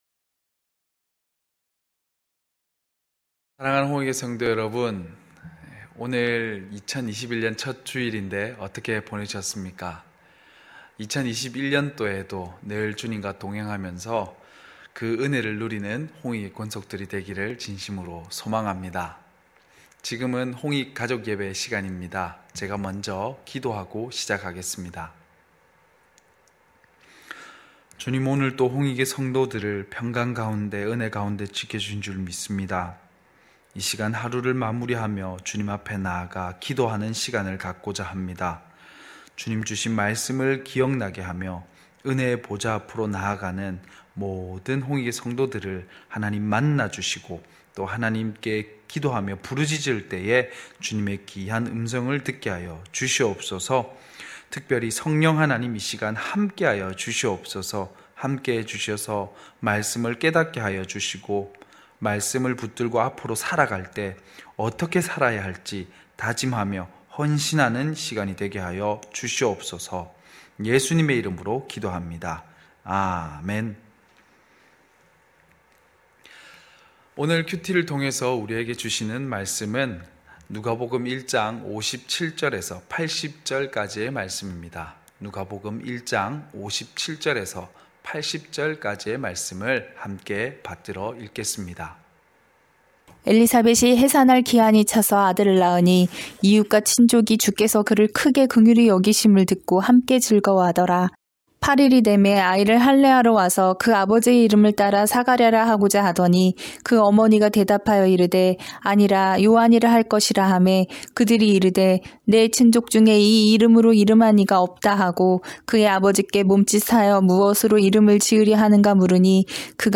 9시홍익가족예배(1월3일).mp3